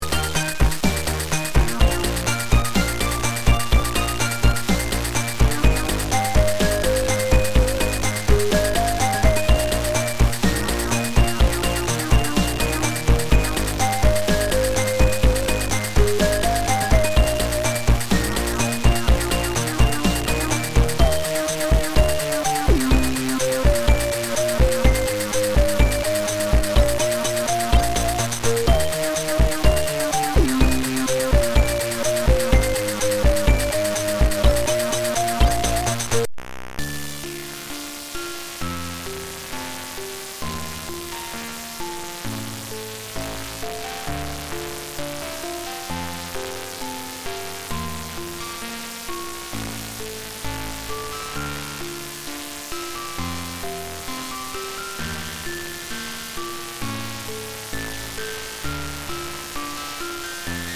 File 2 - This is recording from the zz9000ax with the three cable connector in the default position (as it was specified in the manual).